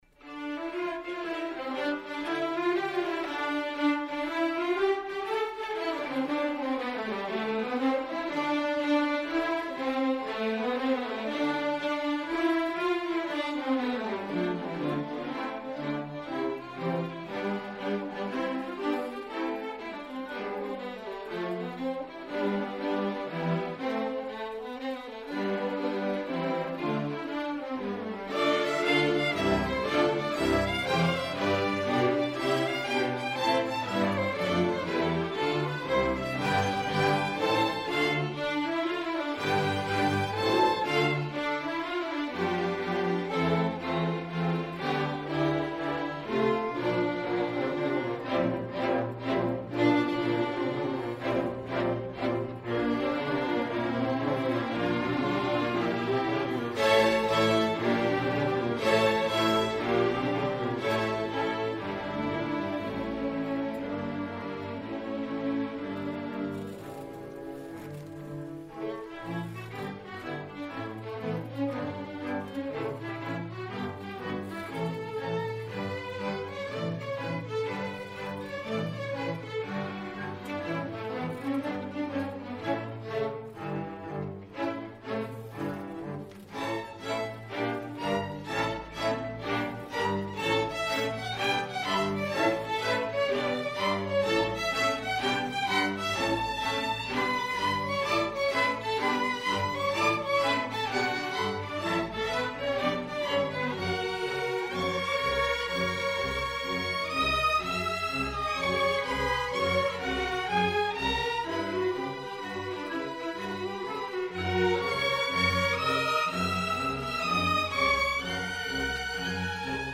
Queen City Community Orchestra
Fall 2022 Concert